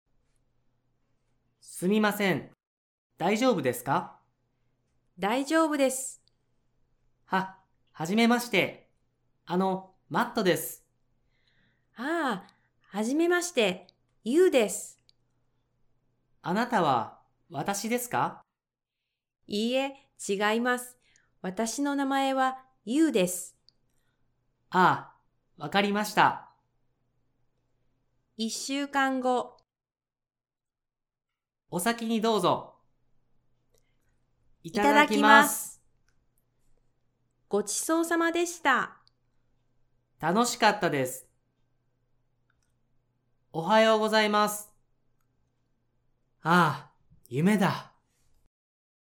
GL1-Conversation.mp3